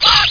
WB_HIT.mp3